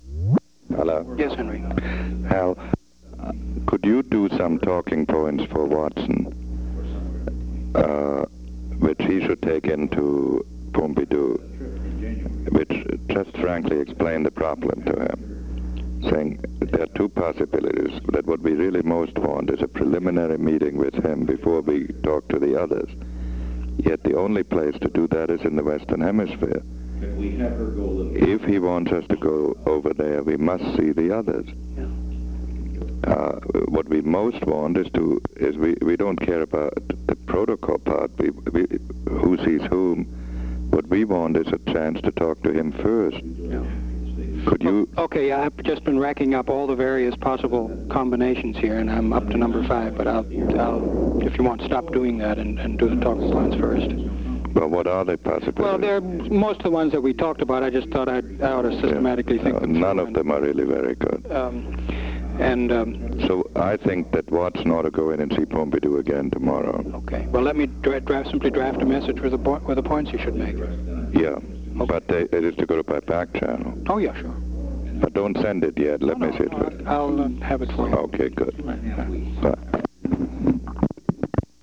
Location: White House Telephone
Henry A. Kissinger talked with Helmut Sonnenfeldt.
[The President can be heard in the background]